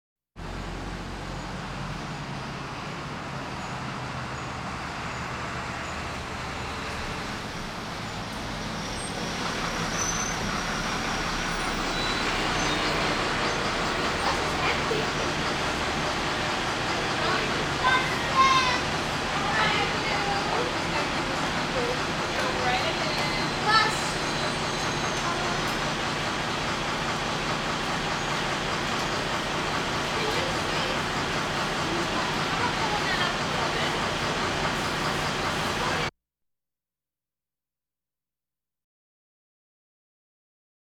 ambience
School Crowd - Bus Pull Up, Children Arrive At School